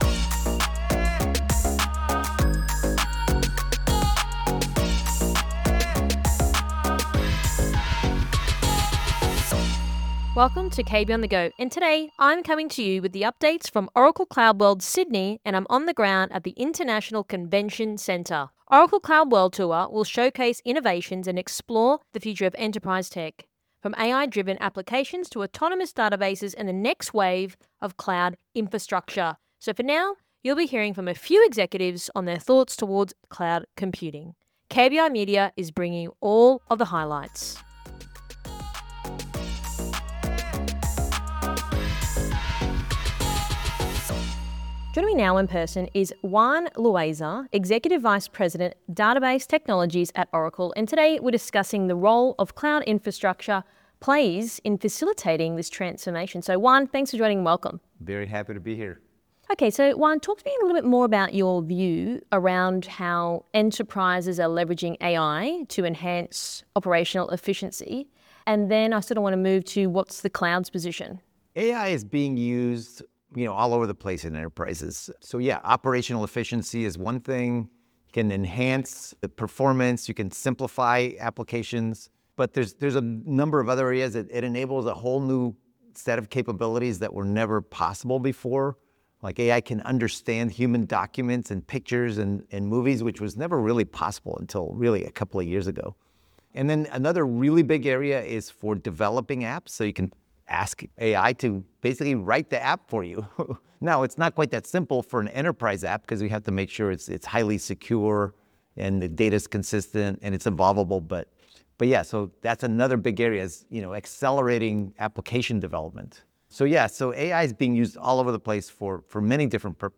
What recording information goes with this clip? From Oracle CloudWorld Tour 2025 Sydney – KB on the Go